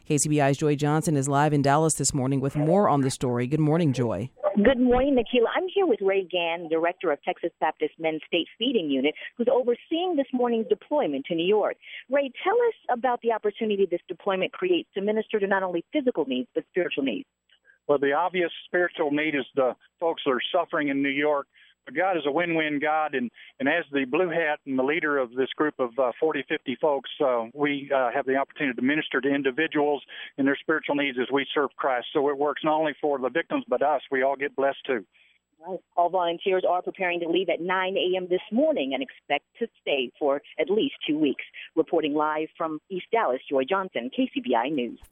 Sandy Volunteers Deployment - Texas Baptist Men Interview